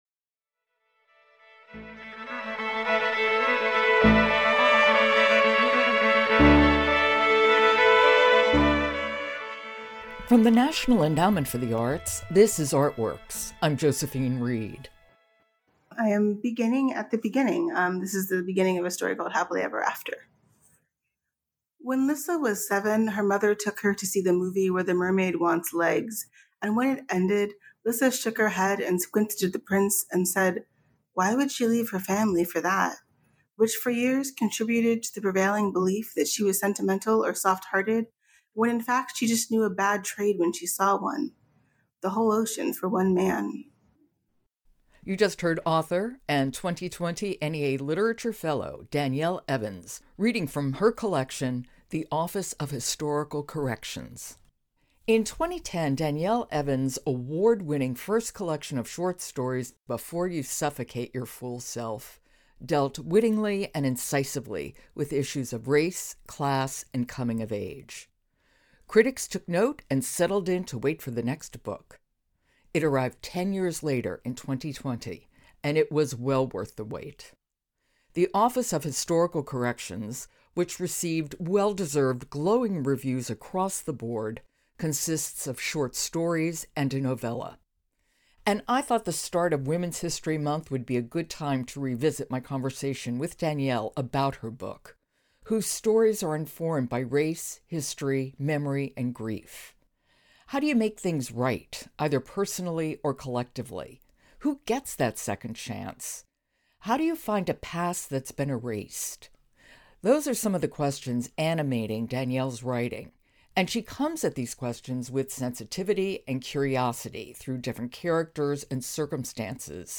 Exploring complexities through short stories: a conversation with author and 2020 NEA Literature Fellow Danielle Evans.